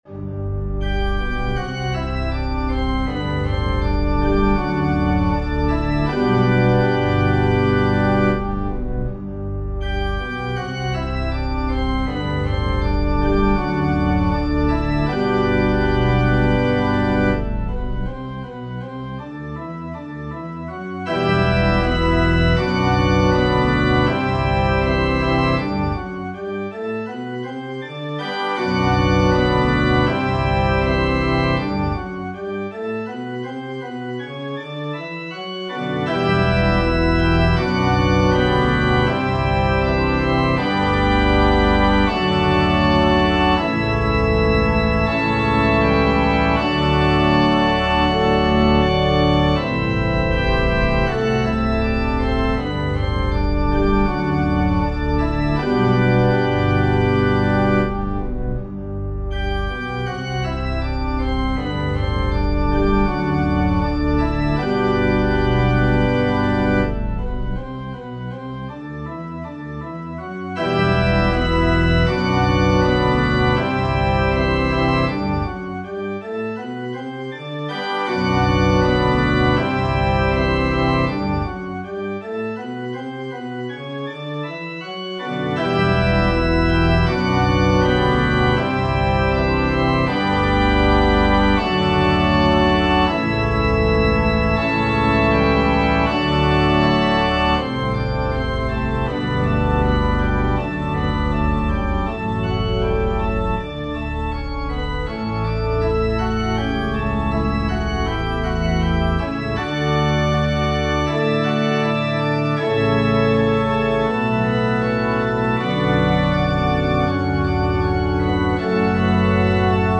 Organ Music